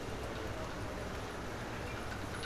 Paris_street1.R.wav